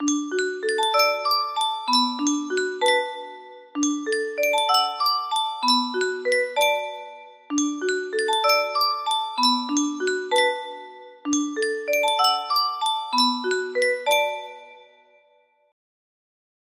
Grand Illusions 30 music boxes More